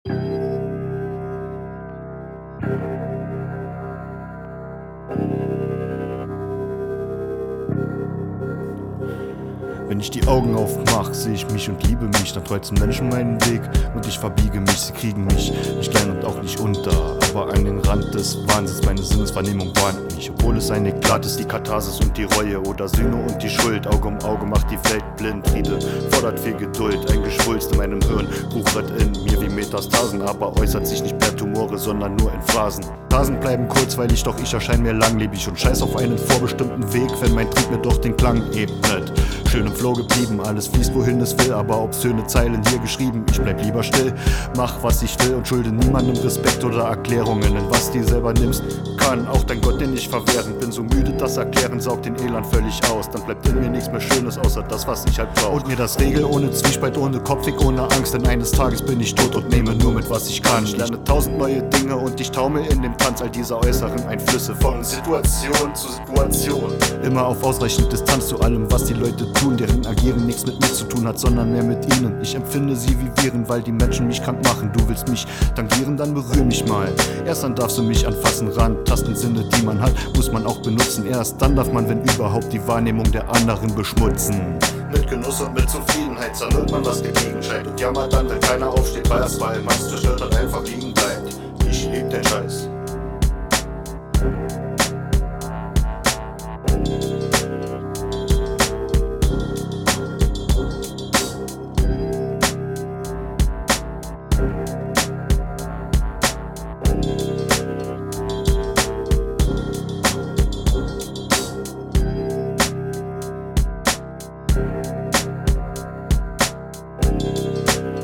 Aktuelles Rap Producer/Artist/Mix Lvl
Bräucht mal wieder Entwicklungshilfe ^^ was meint ihr? ist noch nicht so sauber und perfekt wie ichs gern hätte und mic war das Behringer C1 neue Takes bzw hab ich versucht mit hilfe von punch in einen vernünftigen take zusammen zu bekommen und die komische stimme am ende is absicht nur...
der eine Take war auf 2 Tracks aufgenommen weils so einfacher ist auf der mpc^^ Hab versucht eq und kompressor gleich einzustellen aber man hört das...